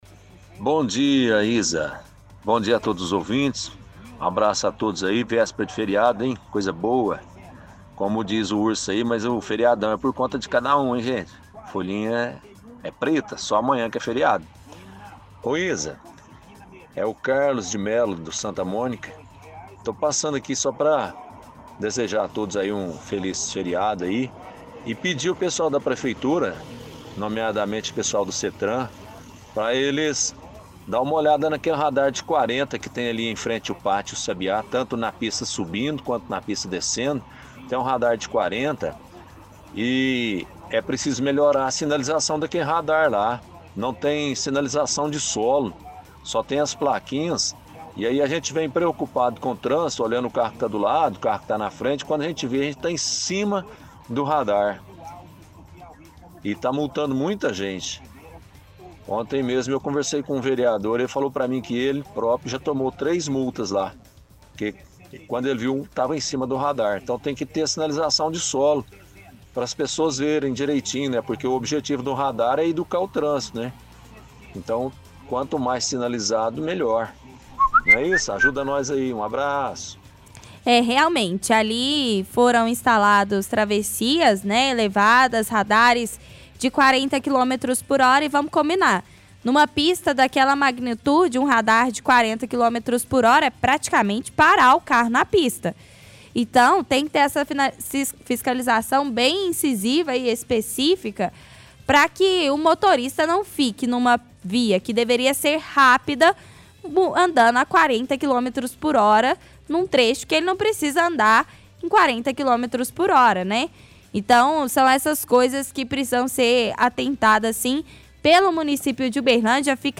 – Ouvinte pede à secretaria de trânsito e transportes para melhorar a sinalização do radar próximo ao pátio do sabiá, diz que está multando muitos motoristas.